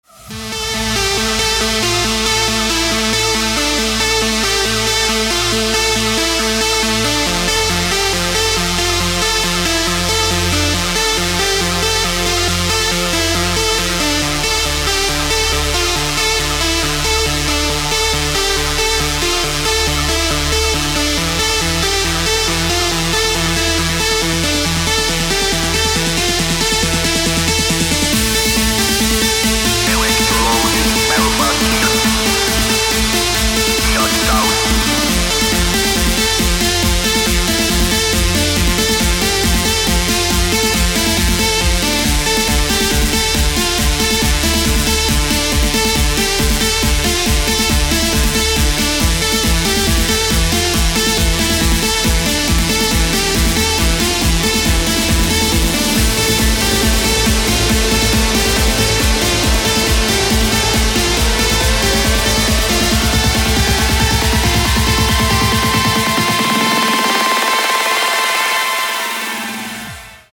• Качество: 224, Stereo
громкие
электронная музыка
club
космические
Trance